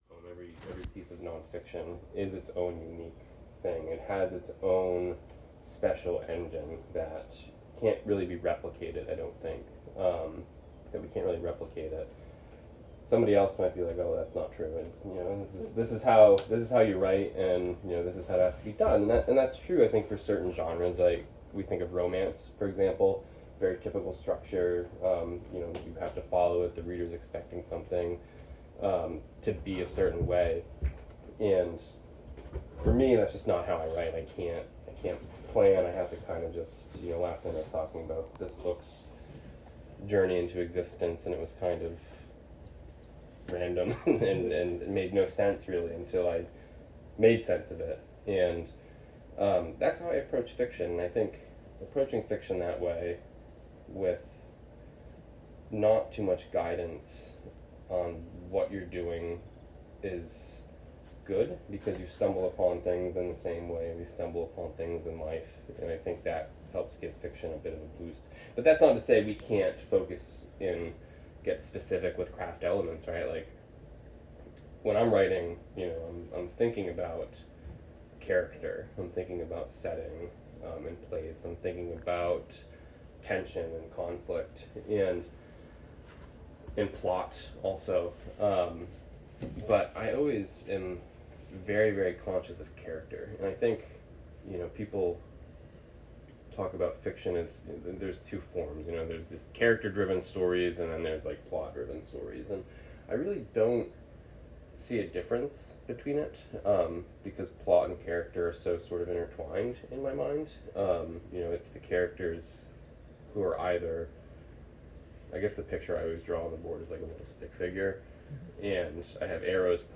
(Please excuse the abrupt beginning of this recording, we had some technical difficulties!) https